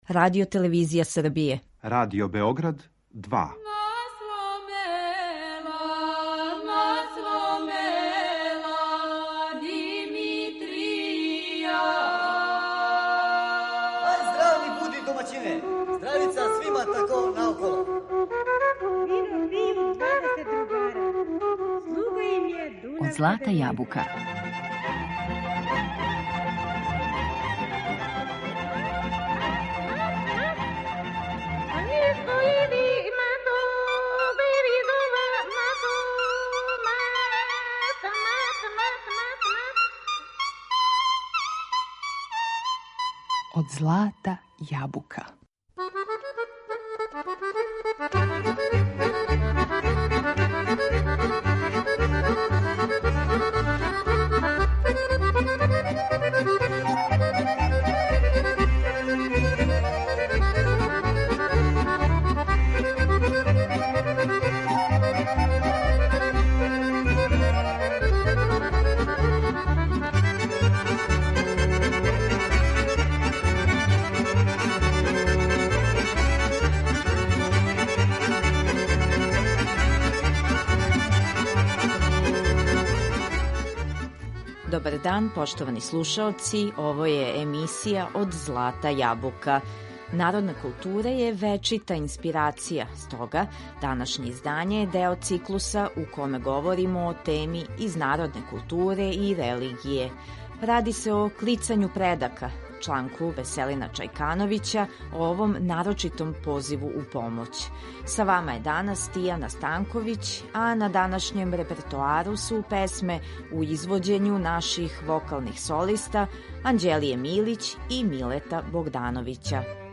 На репертоару су најлепше песме наших вокалних солиста уз пратњу Народног оркестра.